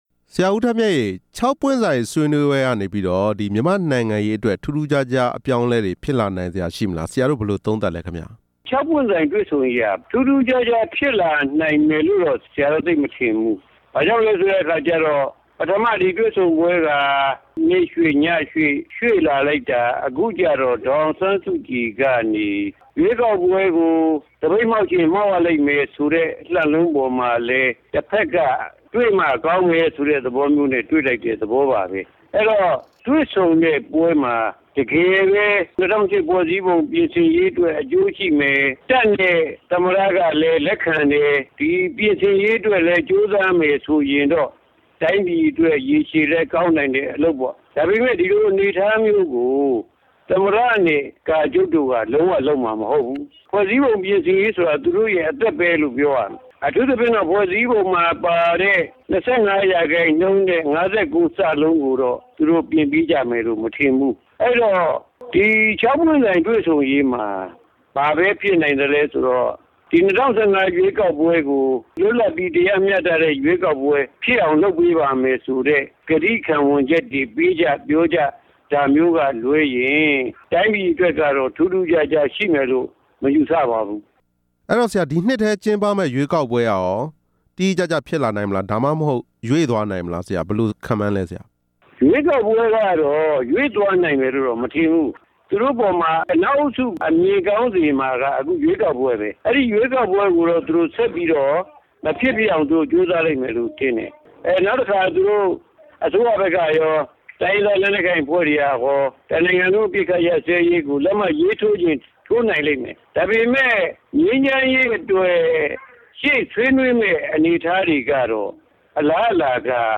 ၆ ပွင့်ဆိုင်ဆွေးနွေးပွဲနဲ့ ၂ဝ၁၅ ရွေးကောက်ပွဲကိစ္စ မေးမြန်းချက်